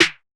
LeanSnare.wav